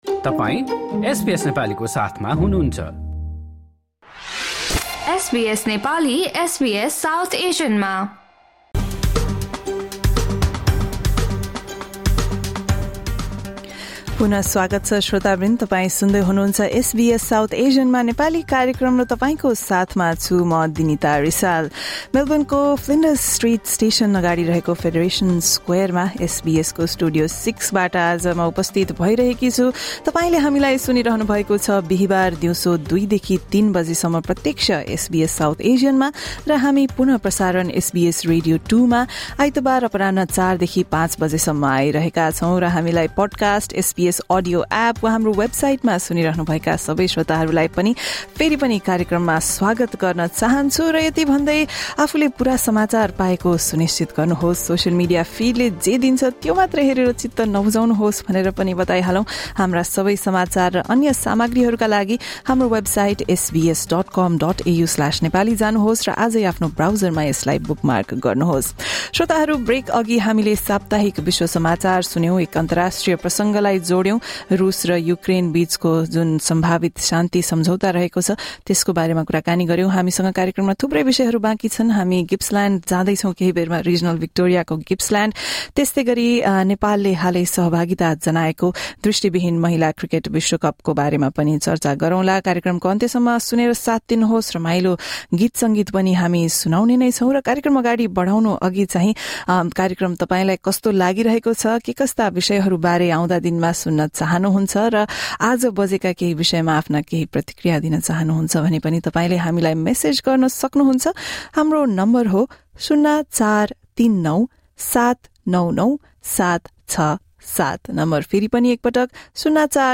During the film's premiere in Melbourne